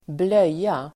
Uttal: [²bl'öj:a]